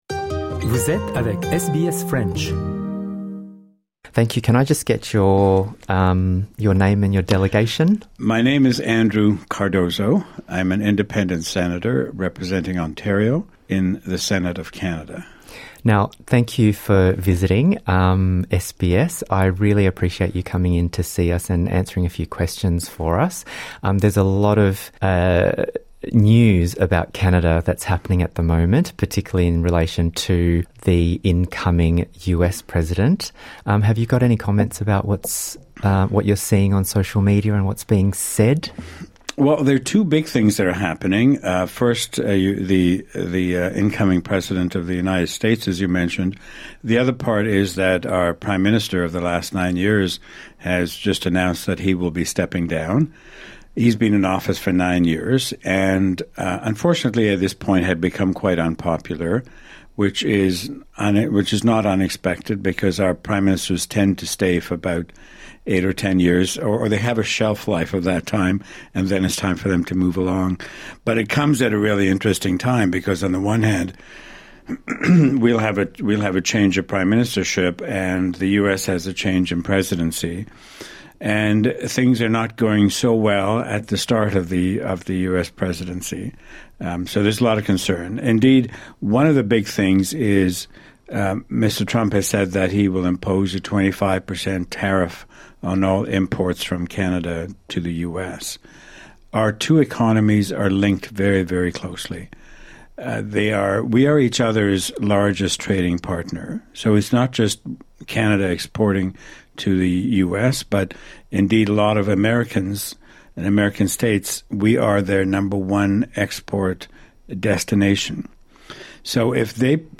Andrew Cardozo, sénateur indépendant représentant l'Ontario au Sénat canadien, en visite en Australie, a été interviewé